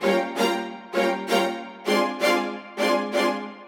Index of /musicradar/gangster-sting-samples/130bpm Loops
GS_Viols_130-GD.wav